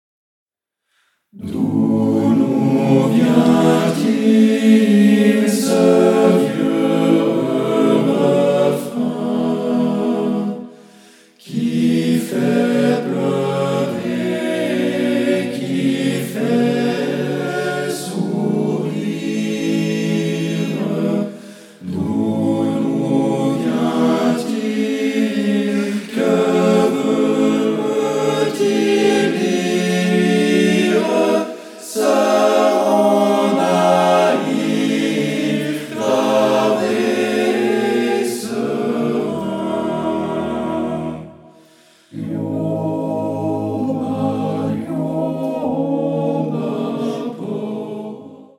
Octuor d’hommes